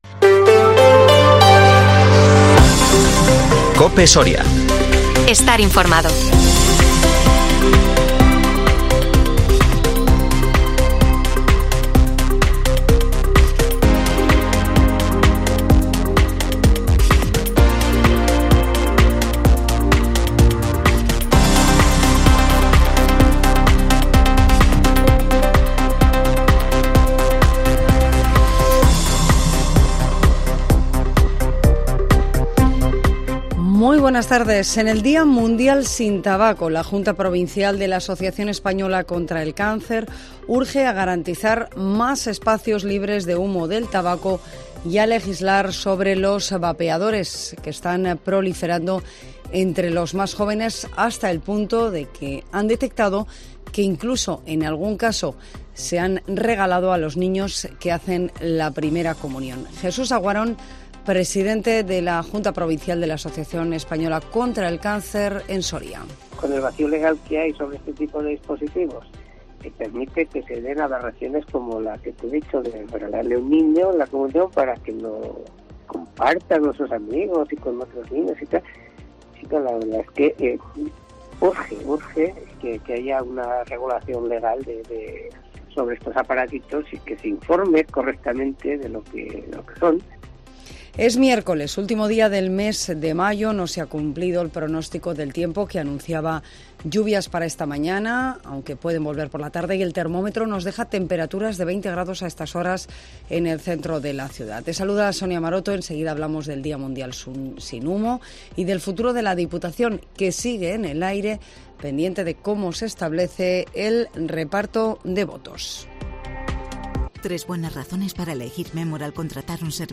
INFORMATIVO MEDIODÍA COPE SORIA 31 MAYO 2023